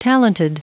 Prononciation du mot talented en anglais (fichier audio)
Prononciation du mot : talented